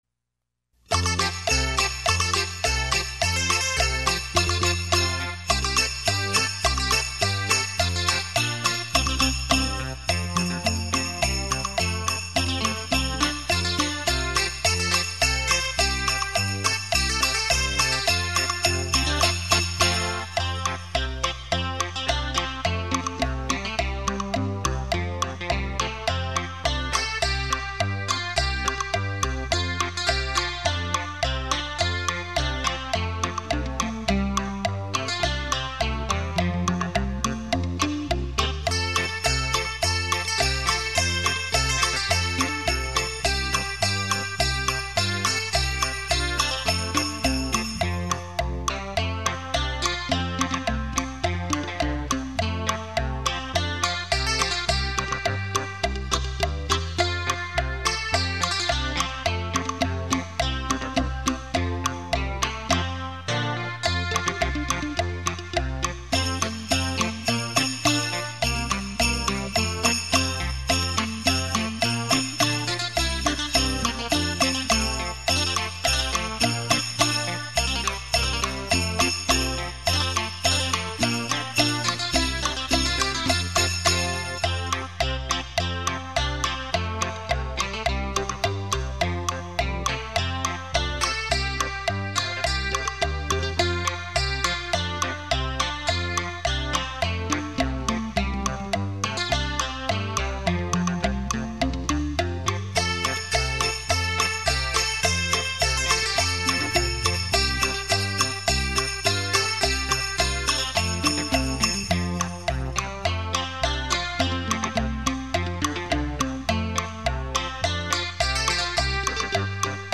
汽车音响测试碟
立体音声 环绕效果
100%绝佳的听觉享受度 100%声历身超级震撼度 100%立体声雷射音效